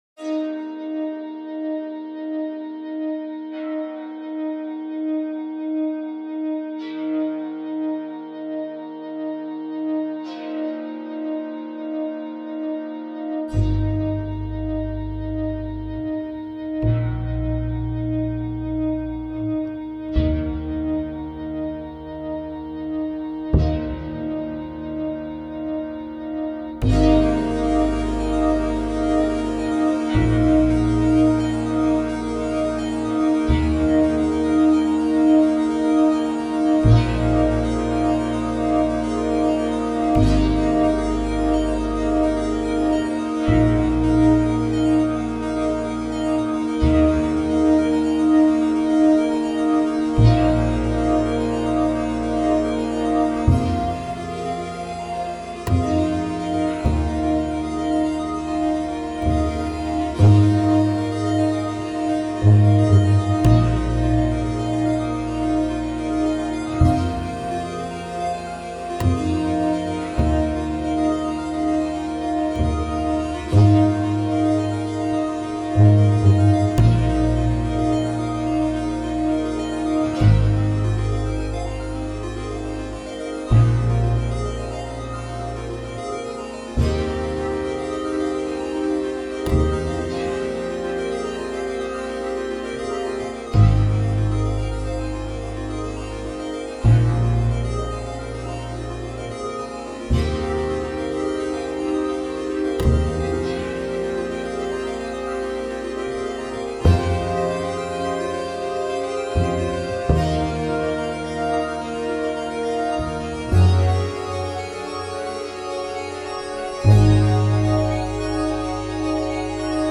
I wanted something slow, spacy, and without a strong beat, and it ended up being neat. In terms of my skillset, this track has two points - to try to combine my improvisational piano skills (if interested see my LJ here which is just me playing piano with no effects) with Ableton Live, and to take a shot at recording my upright bass.
A few tricks here - the keyboard parts are just four bar loops recorded ad-hoc and adjusted slightly for timing. Then I took the notes and played it over three tracks - an FM synth in the middle with chorus/delay on it and two NES-style synths (that I use on darn near everything) with arpeggiators, panned hard left and right, with the same timing but different note order. bass was then recorded, and then everything dropped from Ableton into Sonar for improved mixing, with a fuzz guitar overdub added (it's pretty subtle, as it's backgrounded fairly heavily). Everything except the bass is heavily reverbed (there's even a little on the bass). I also did the trick where I do a slow fade out at the end but pull up the reverb so it sounds like the mix is getting misty and distant at the end before the volume finally drops. I only do it to the synths, which accounts for how the bass goes down first and causes this shimmery quality.
I really love the spacey synth.